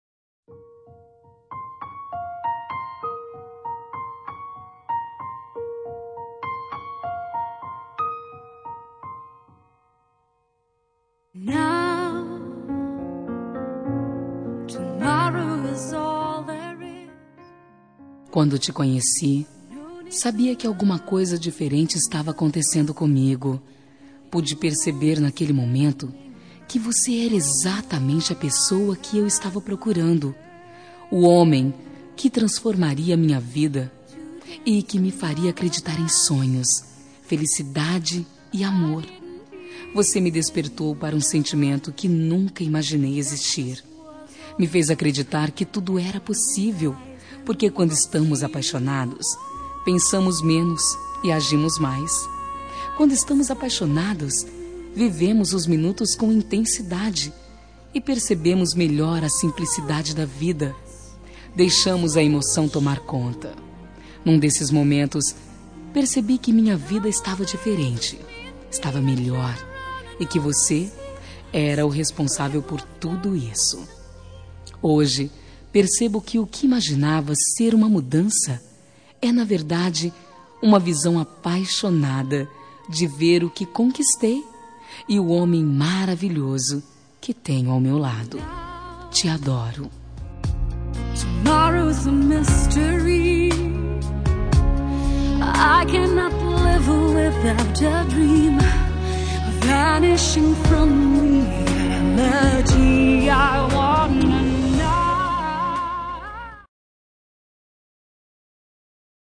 Telemensagem Romântica – Voz Feminina – Cód: 559 Linda